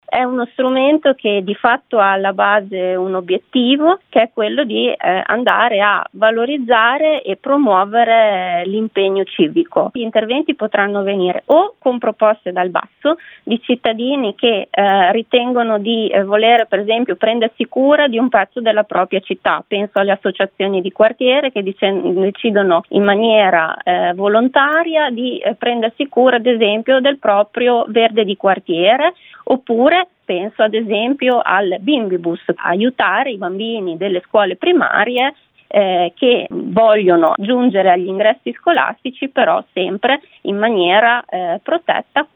A spiegare il progetto l’assessora all’associazionismo di Maranello, Chiara Ferrari: